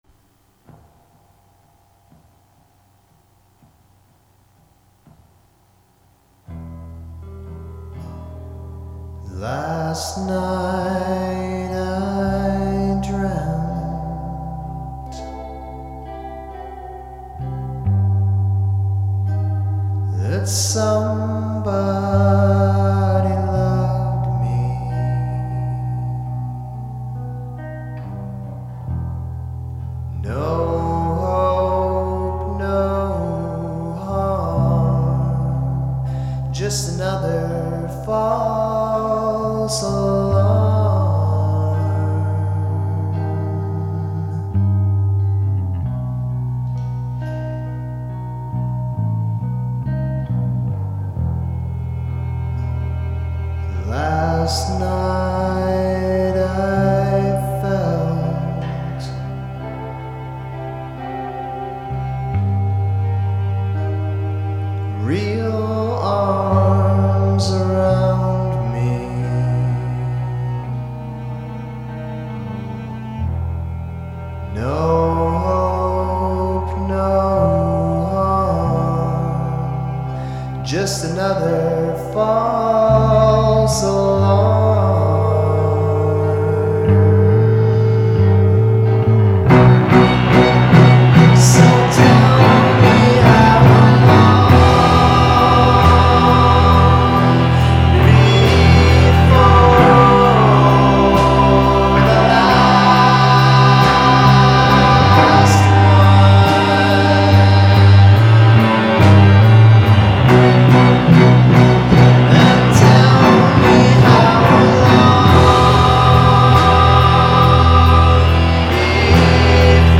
Soaked in reverb, bathed in pathos
Gothic cowboy music at its very best.